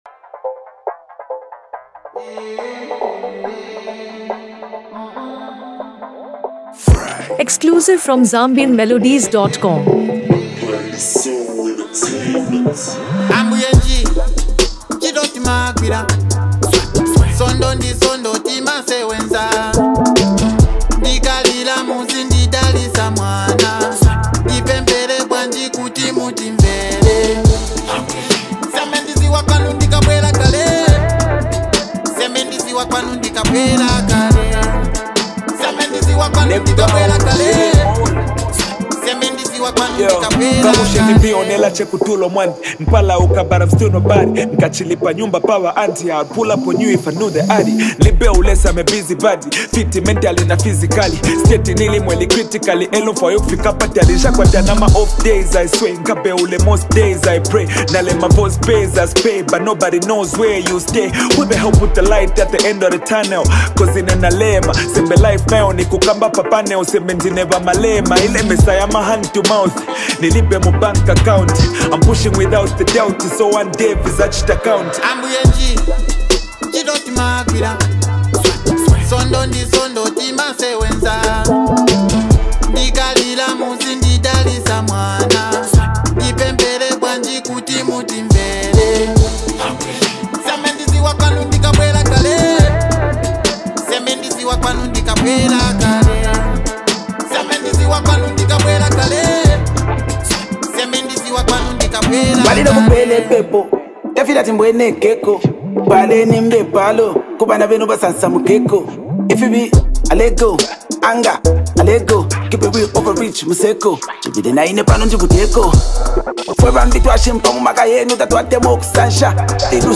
hip-hop
Belonging to the hip-hop and Afro-fusion genre
smooth production